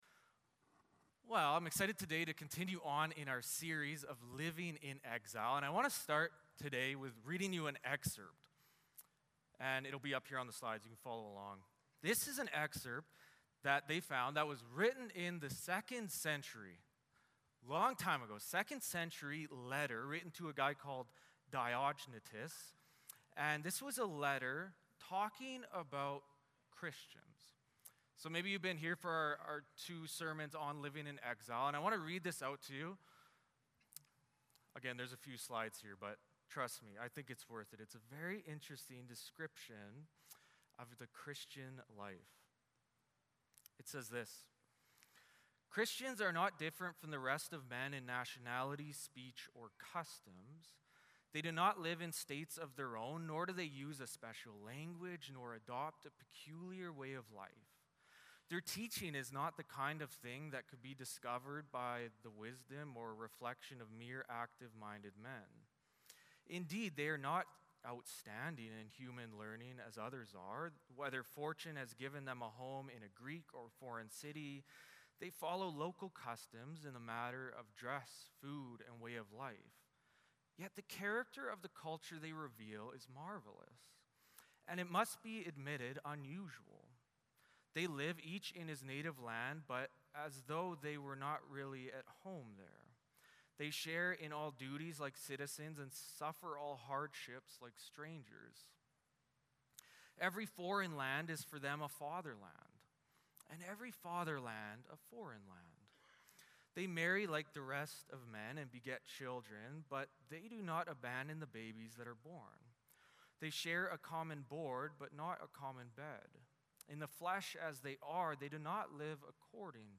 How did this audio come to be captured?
Jeremiah 29:4-7 & 30:12-17 Service Type: Sunday Morning Service Passage